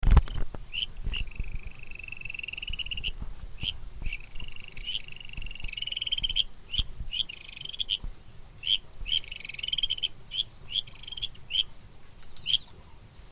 日本樹蛙 Buergeria japonica
花蓮縣 壽豐鄉 193縣道42K
次生林旁水溝
10隻以上競叫